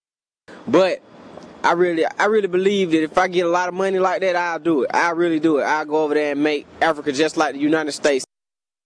Fourthly, an answer typical of informal speech.
This example simply illustrates that we often use repetition for hesitation, and to put emphasize on certain ideas or intentions.